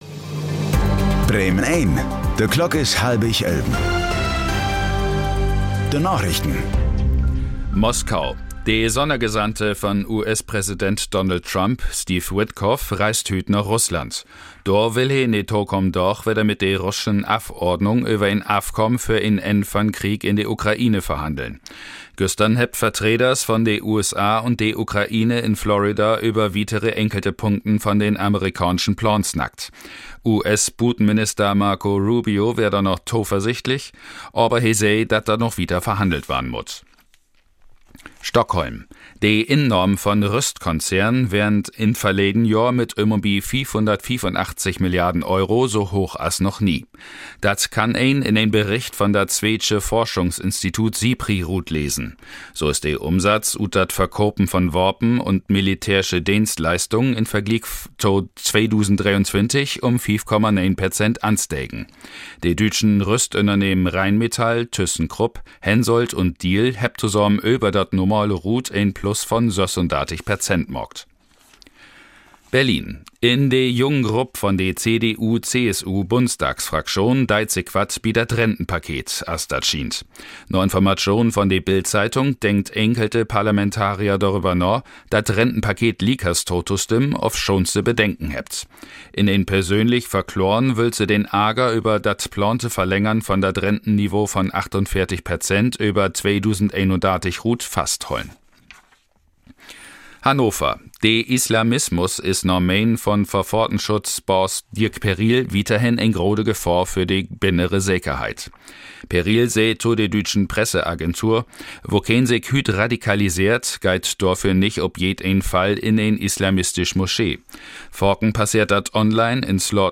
Plattdüütsche Narichten vun'n 1. Dezember 2025